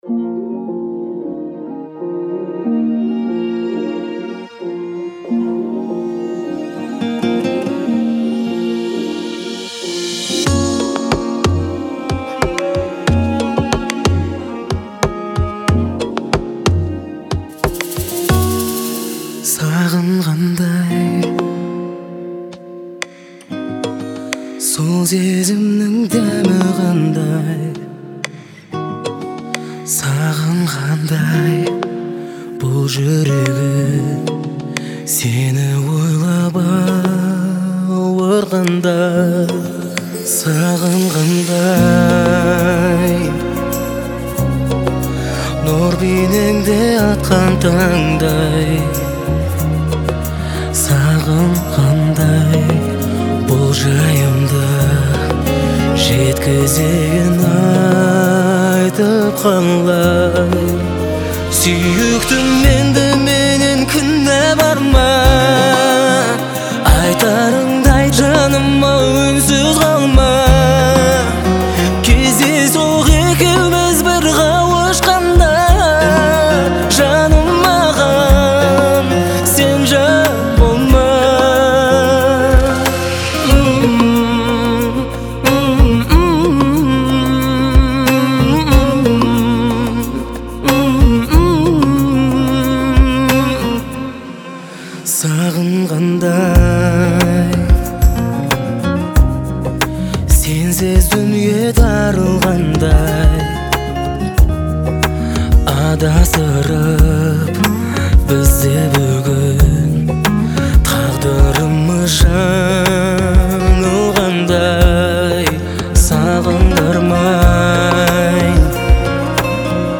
это трогательная песня в жанре казахской поп-музыки
Звучание отличается мелодичностью и гармоничными аккордами